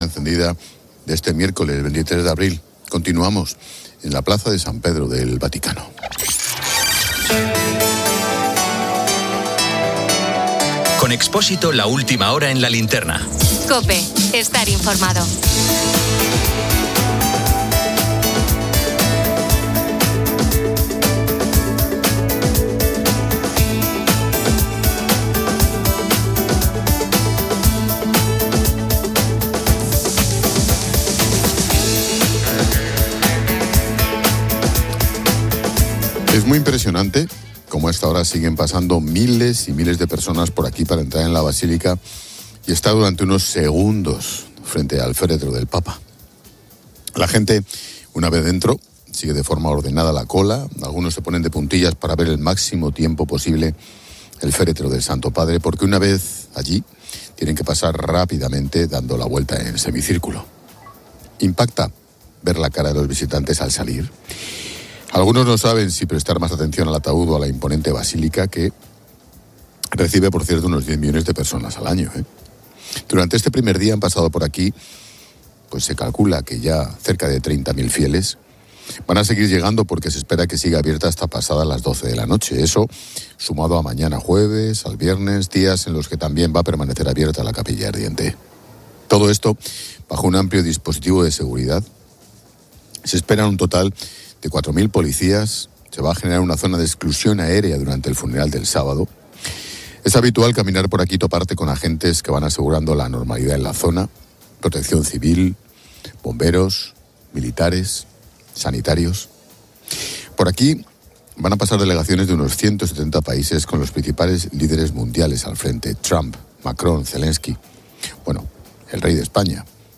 Continuamos en la Plaza de San Pedro del Vaticano.